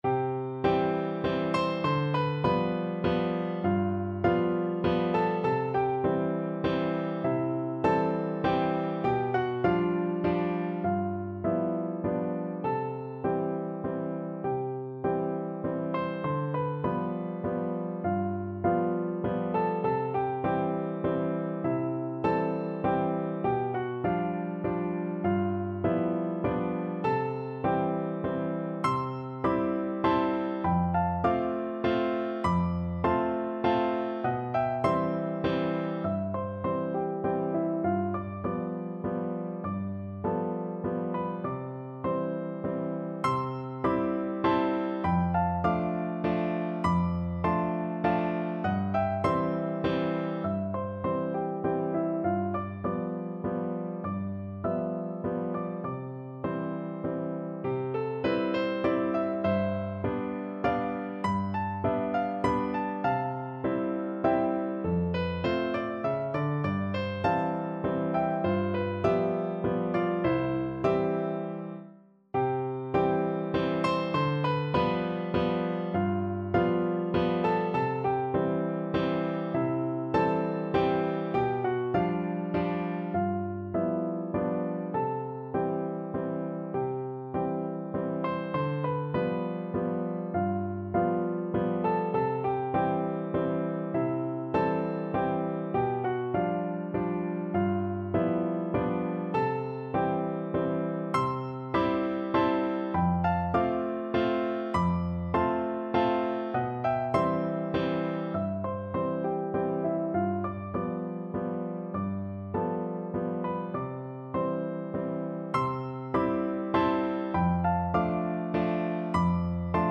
Free Sheet music for Piano Four Hands (Piano Duet)
Santa Lucia is a traditional Neapolitan song.
3/4 (View more 3/4 Music)
C major (Sounding Pitch) (View more C major Music for Piano Duet )
Andantino (View more music marked Andantino)
Traditional (View more Traditional Piano Duet Music)